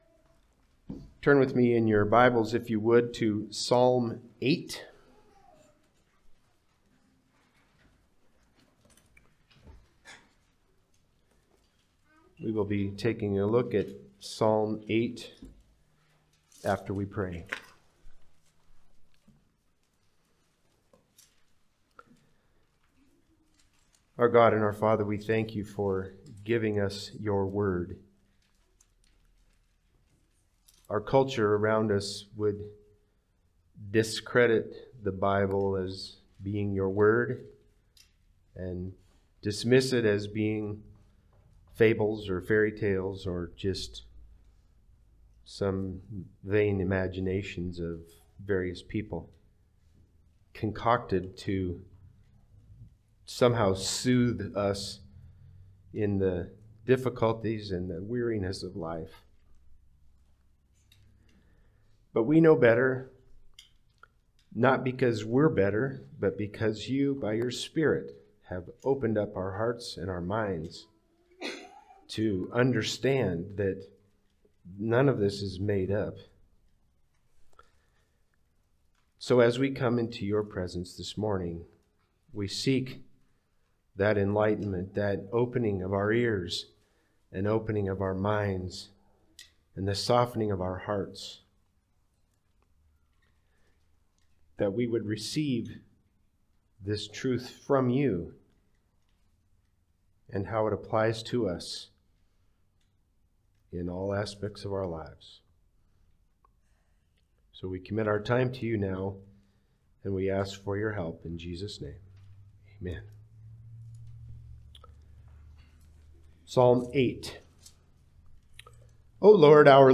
Passage: Psalm 8 Service Type: Sunday Service